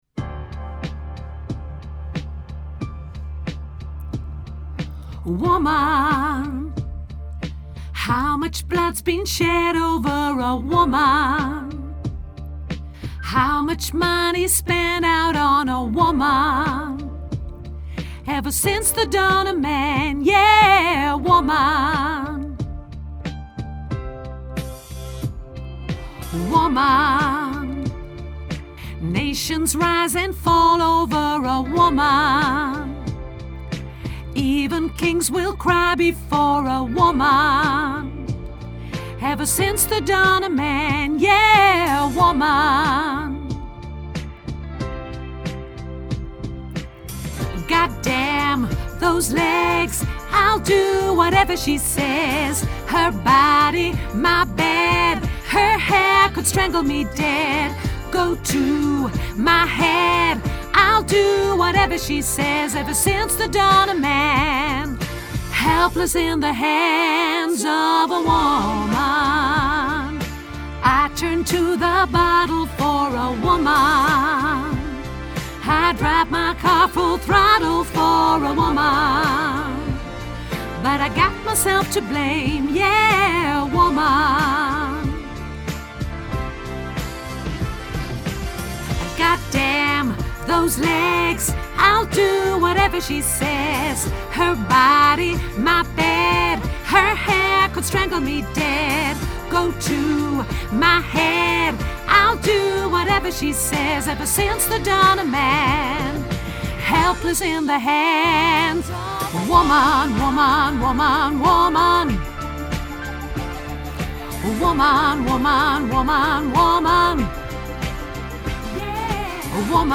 Woman Bas Grote Koor Mp 3